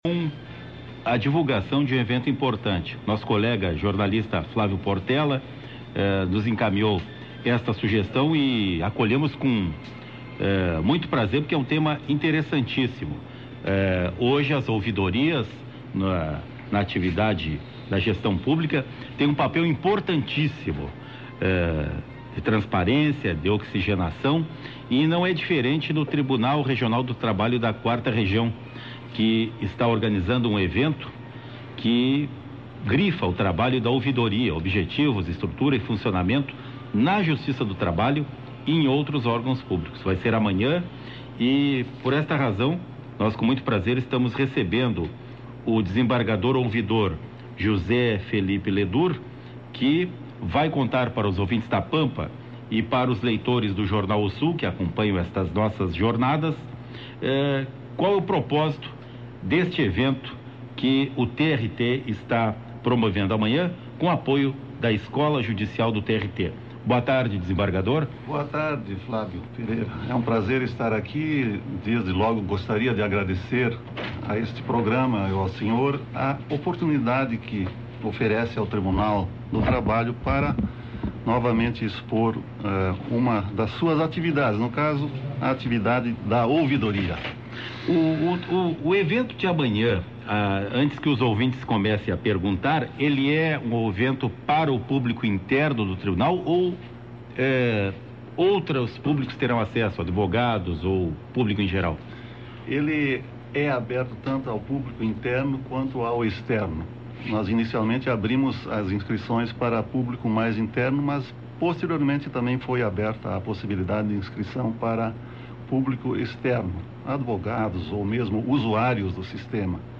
Rádio Pampa: Des. Ledur concede entrevista